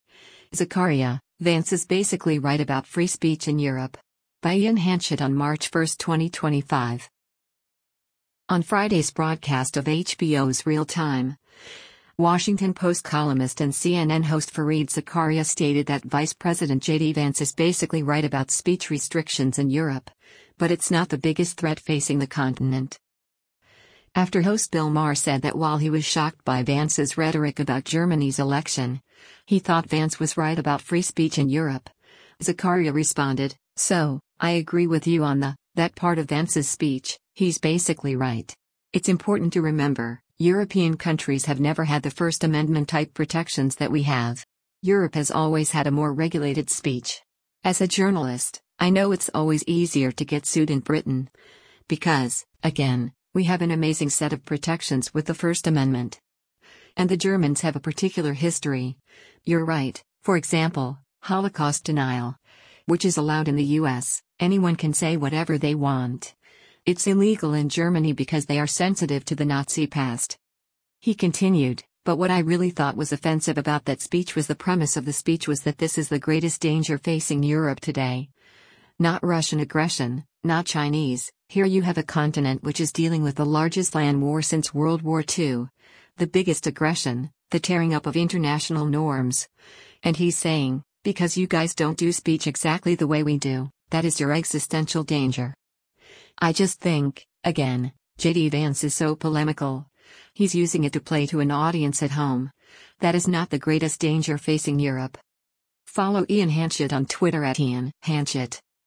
On Friday’s broadcast of HBO’s “Real Time,” Washington Post columnist and CNN host Fareed Zakaria stated that Vice President JD Vance is “basically right” about speech restrictions in Europe, but it’s not the biggest threat facing the continent.